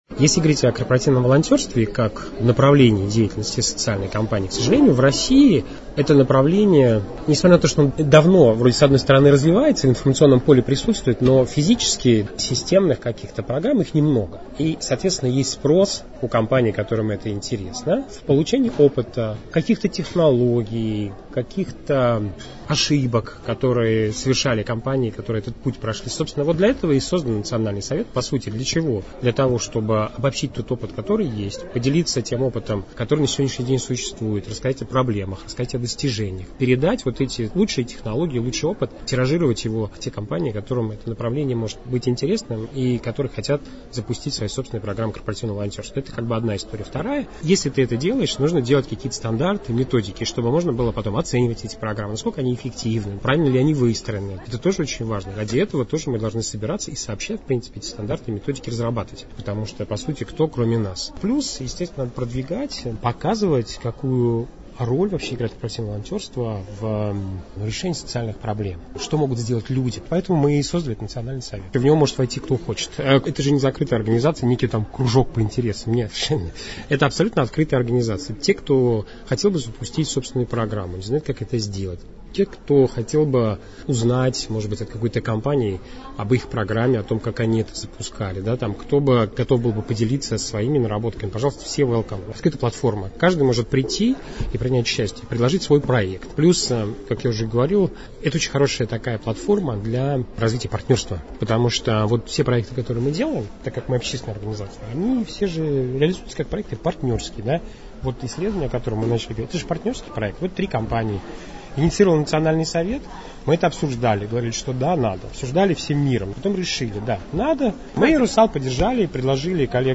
«Расскажем» — аудиопроект Агентства социальной информации: живые комментарии экспертов некоммерческого сектора на актуальные темы.